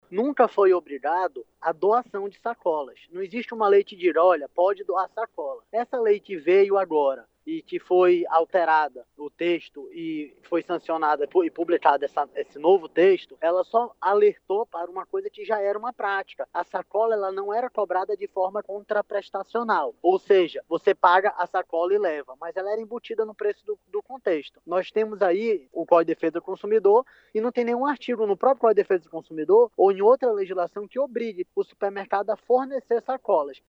Ao pé da letra, na lei que já está em vigor não há nada que impeça a cobrança pelo produto, como orienta o gestor do Procon Amazonas Jalil Fraxe.